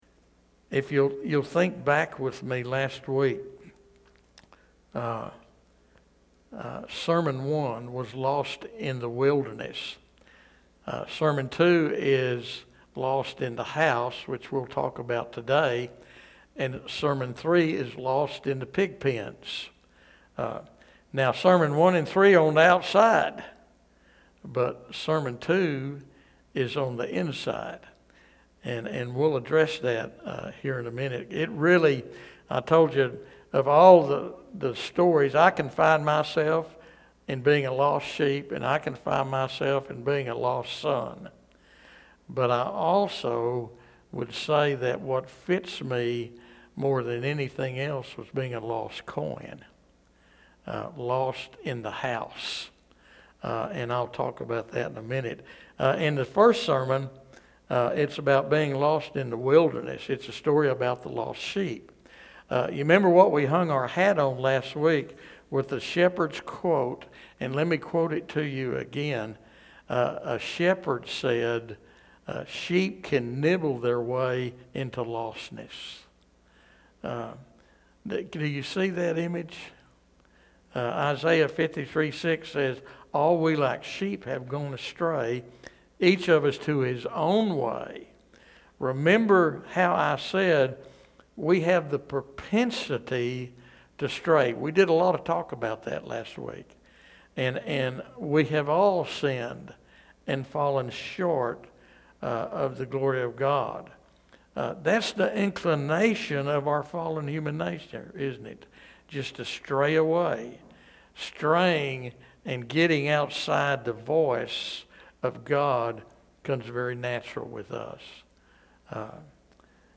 This sermon examines: